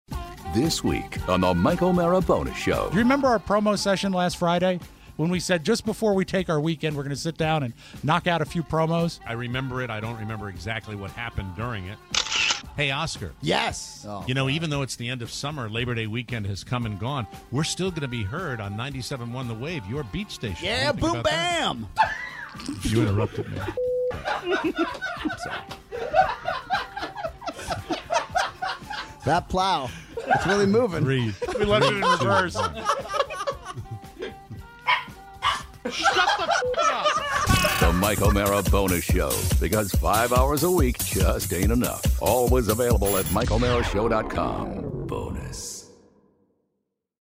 When the mic are off, the show still goes on and boy do we have some great post-show audio for you! Plus… time travel, Stranger Things and holes of black and worm!